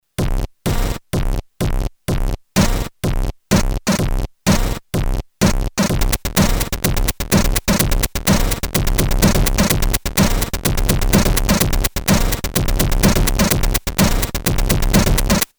Sampling with Commodore 64